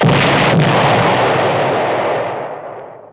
Explo1.wav